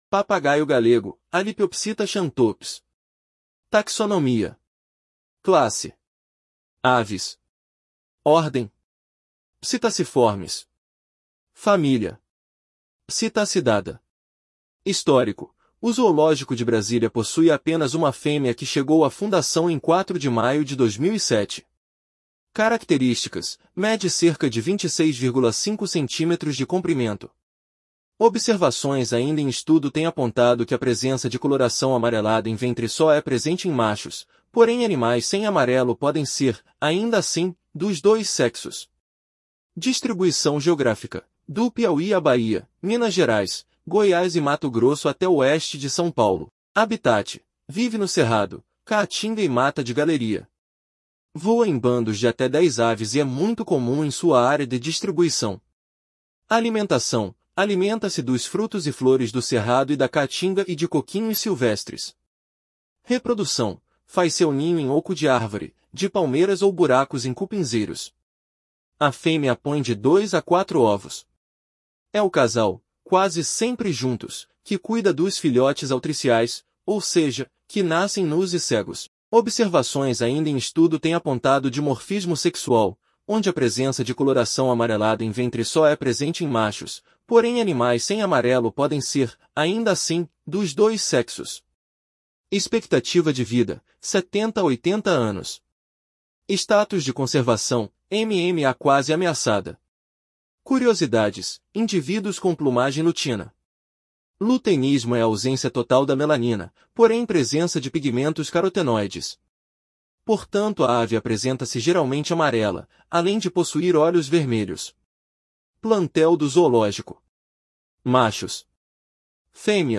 Papagaio-galego (Alipiopsitta xanthops)